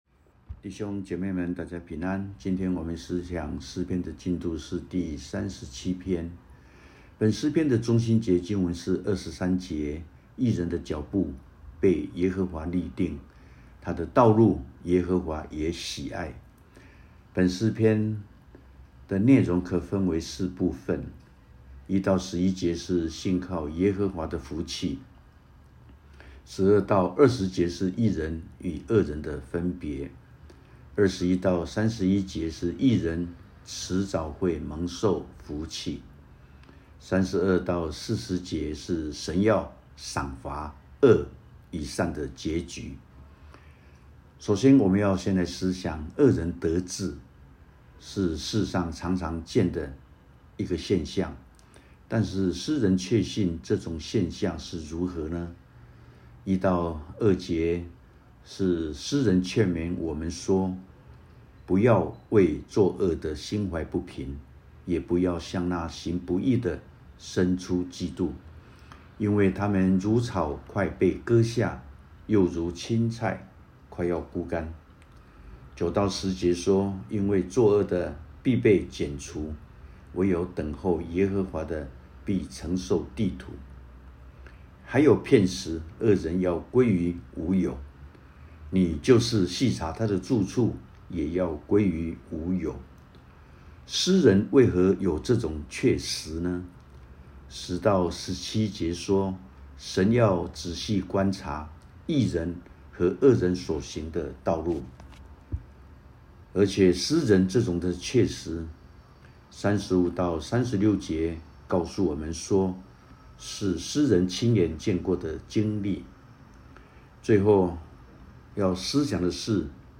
主日禮拜 – “重擔為何放不下?” /主日經文：希伯來書：十二1~2 & 腓立比書：四6